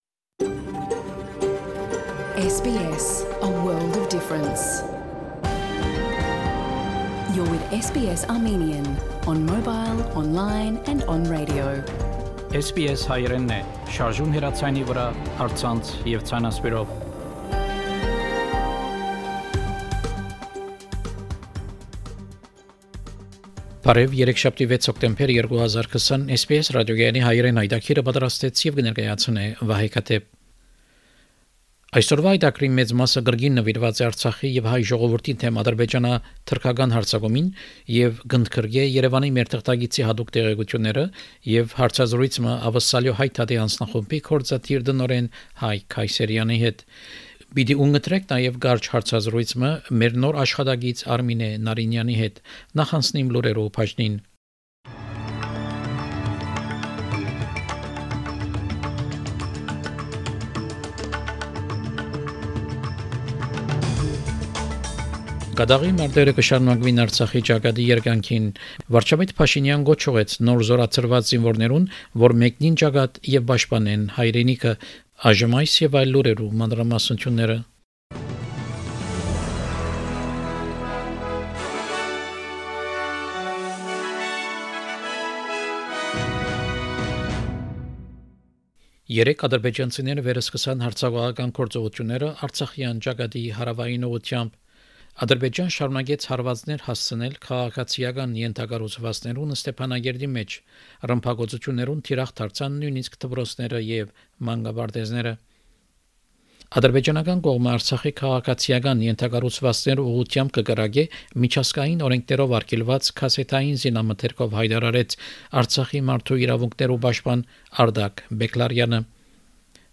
SBS Armenian news bulletin from 6 October 2020 program.